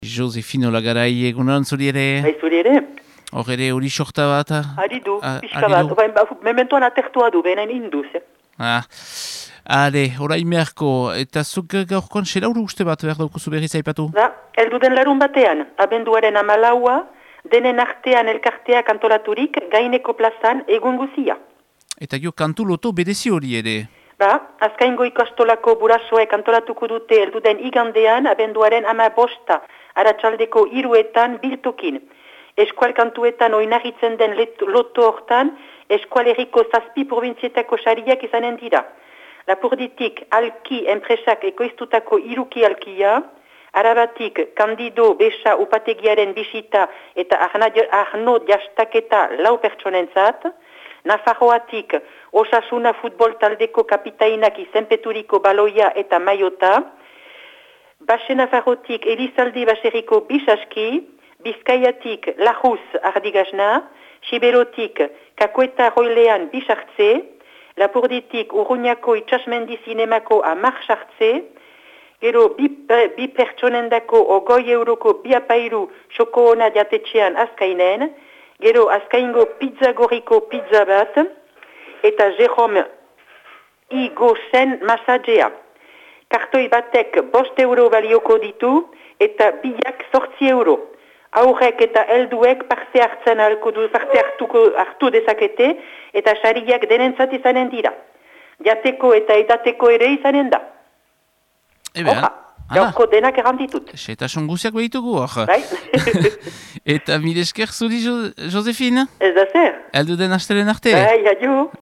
laguntzailearen berriak.